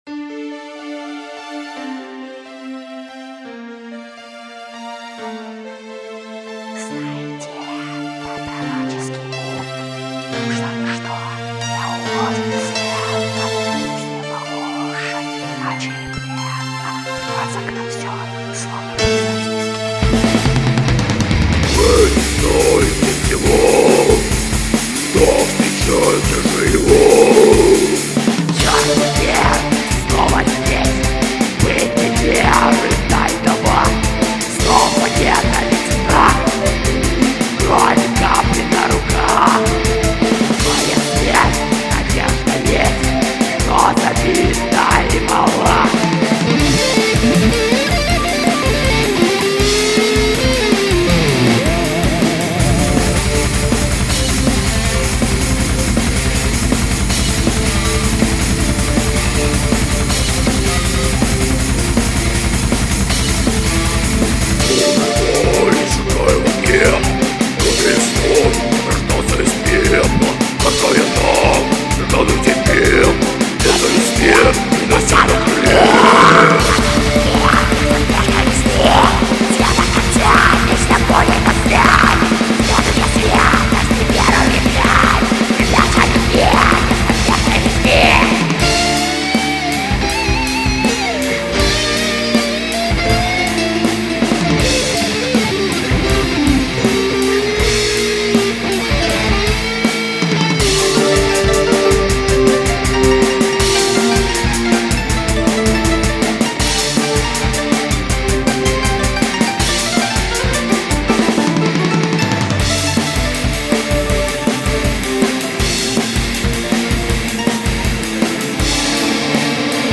��������� ����� ���� � ����� Black\death\doom..